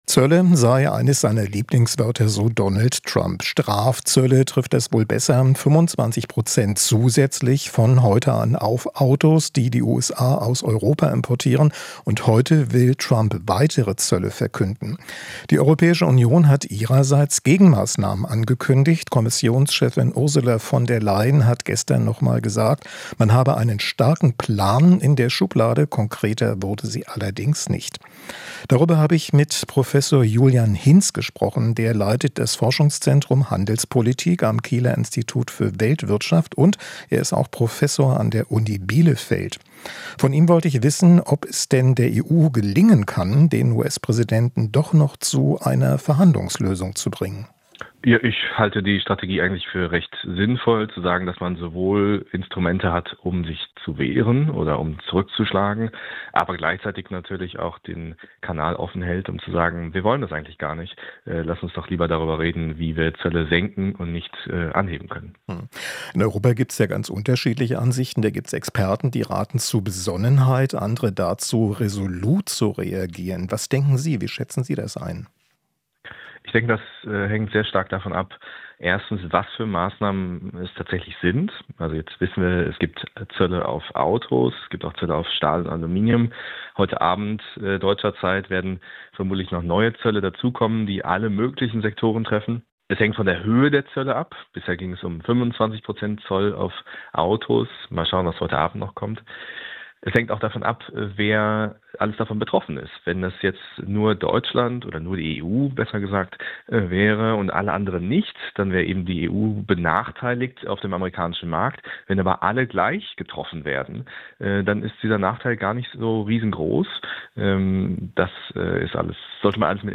Interview - Wirtschaftsforscher: US-Zölle treffen vor allem Amerikaner